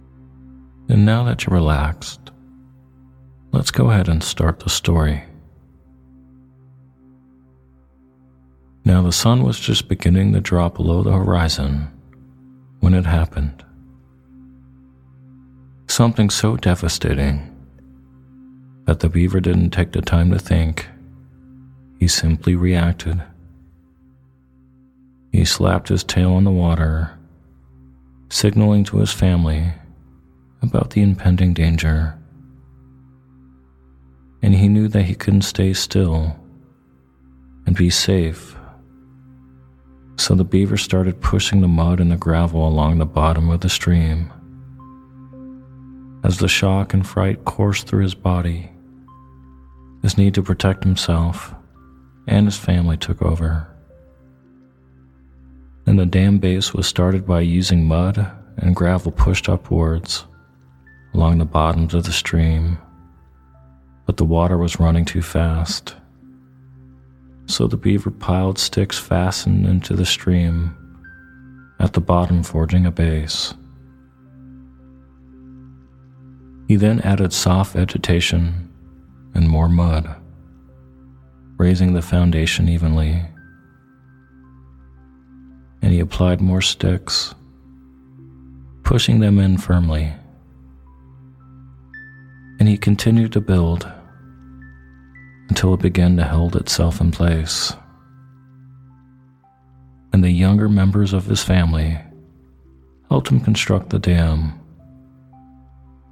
Story Based Meditation "Removing The Dam"
In this story based meditation, you’ll be guided with the metaphorical imagery of removing a dam made by a beaver. This meditation is great for removing blocks, barriers or fear.